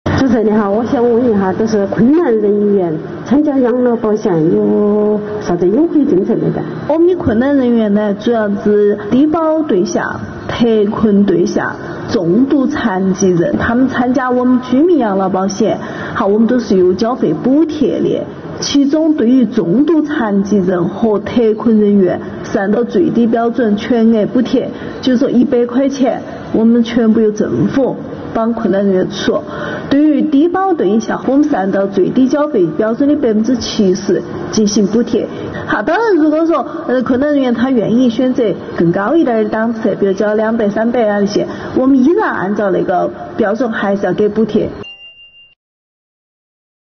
现场市民提问